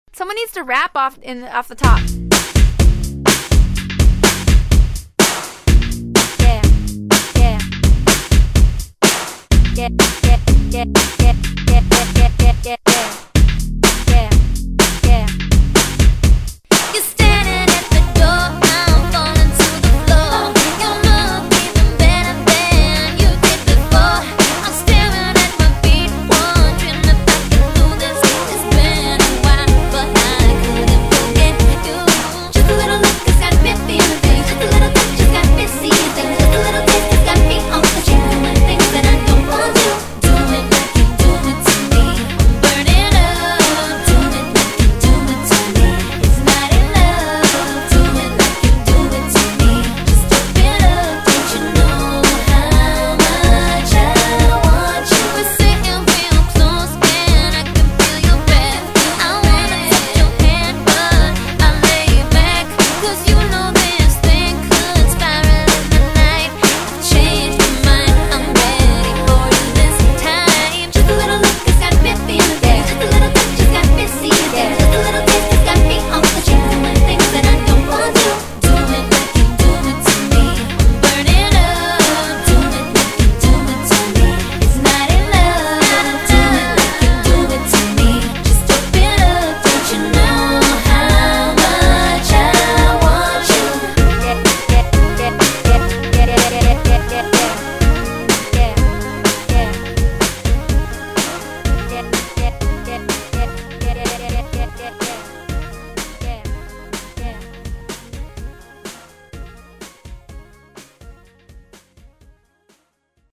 BPM125--1
Audio QualityPerfect (High Quality)